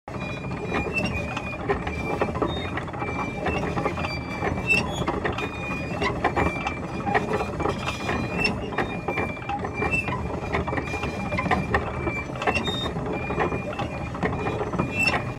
دانلود آهنگ تانک 3 از افکت صوتی حمل و نقل
دانلود صدای تانک 3 از ساعد نیوز با لینک مستقیم و کیفیت بالا
جلوه های صوتی